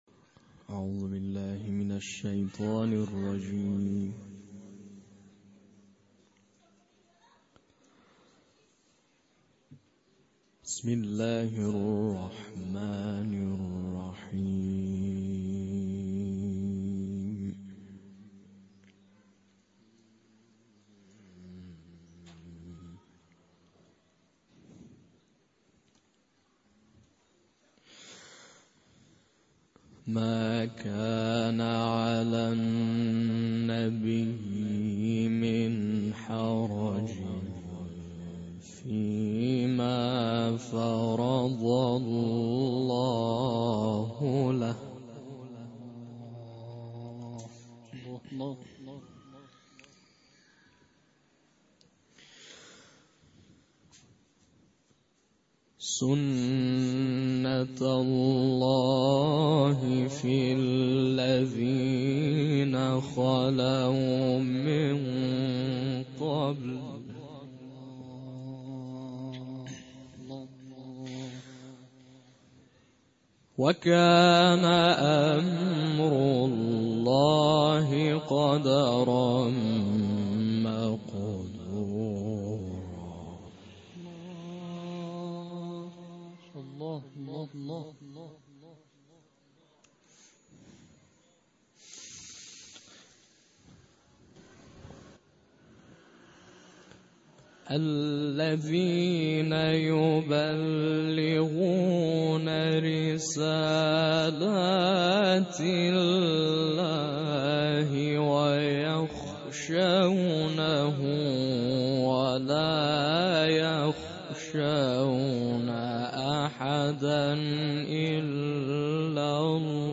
قرائت سوره مبارکه احزاب از آیه ۳۸